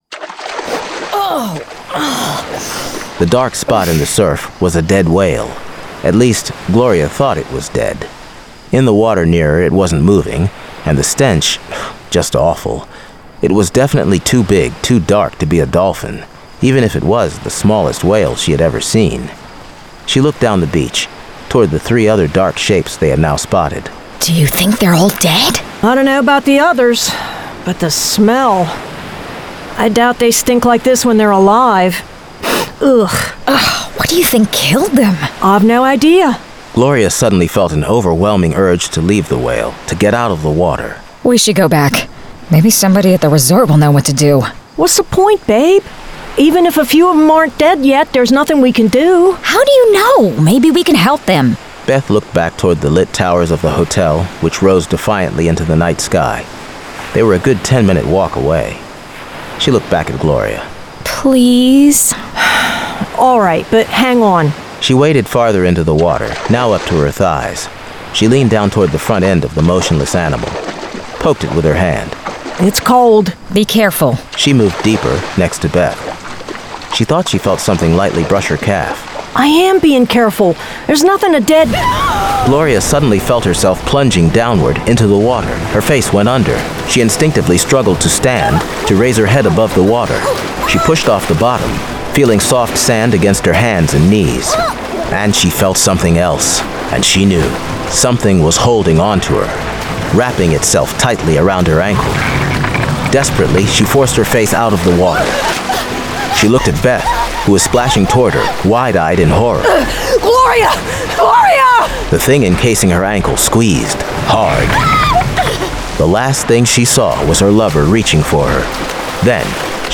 Full Cast. Cinematic Music. Sound Effects.
Genre: Horror